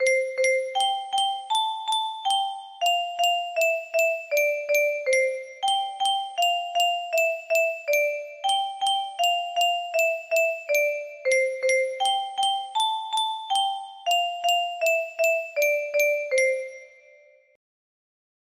⚠ music box melody